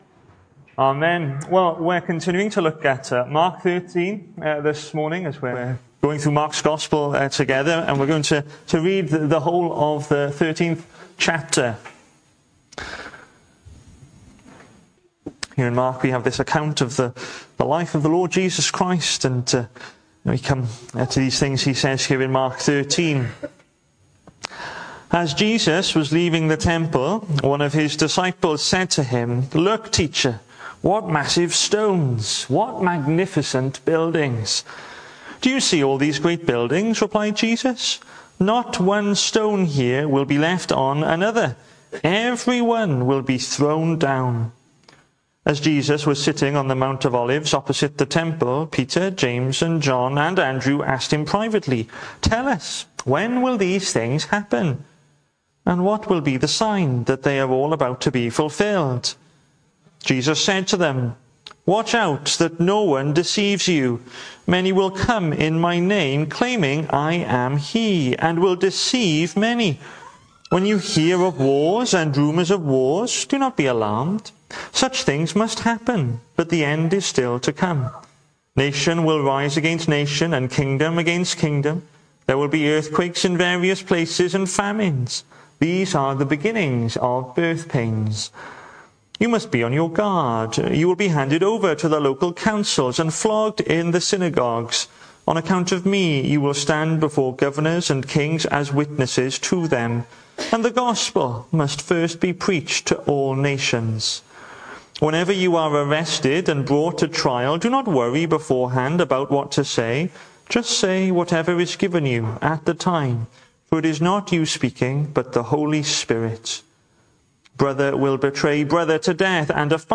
Hello and welcome to Bethel Evangelical Church in Gorseinon and thank you for checking out this weeks sermon recordings.
The 21st of September saw us host our Sunday morning service from the church building, with a livestream available via Facebook.